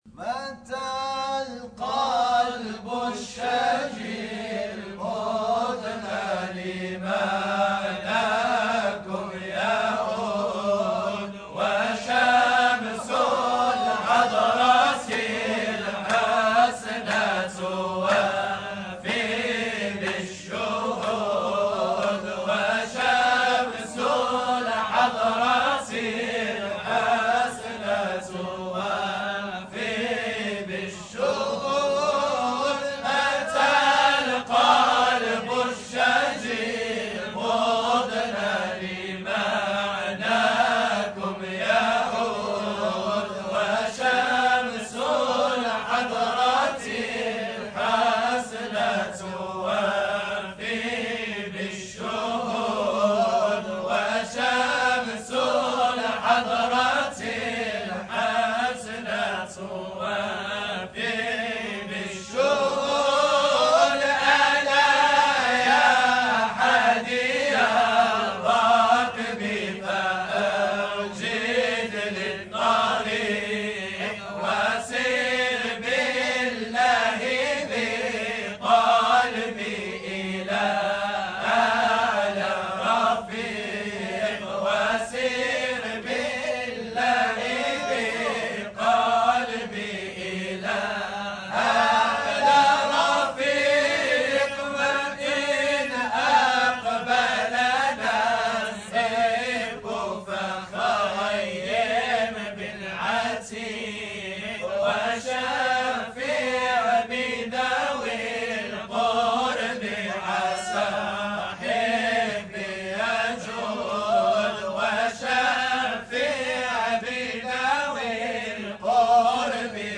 woking_Hadra_4.mp3